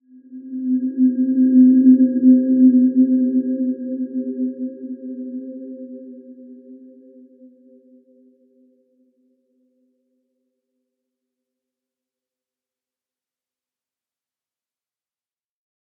Dreamy-Fifths-C4-mf.wav